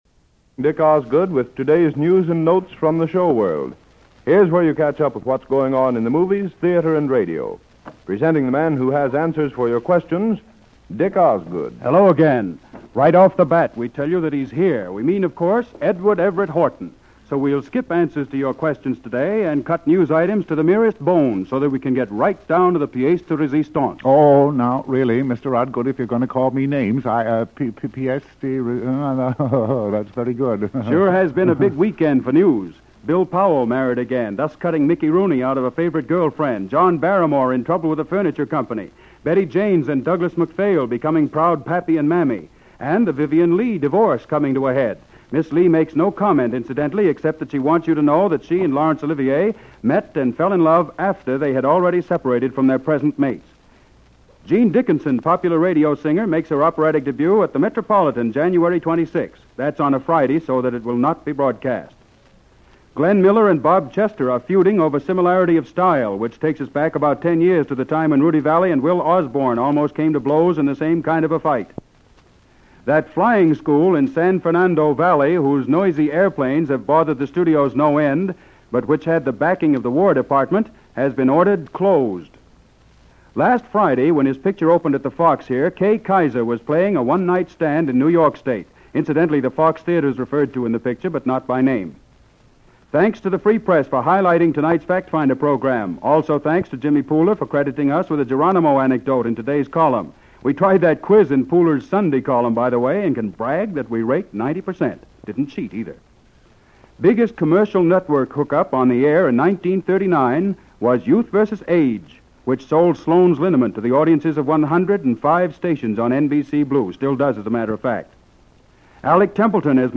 Recently, I came across this 15-minute live interview from 1940, apparently unscripted, where Horton publicizes his local appearance on the stage as the star of SPRINGTIME FOR HENRY. He’s quite the raconteur!
edward-ev-horton-radio-interview-1940.mp3